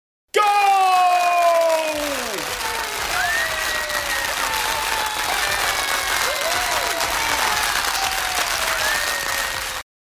В коллекции представлены варианты как от одного человека, так и от целой толпы.
Гол с аплодисментами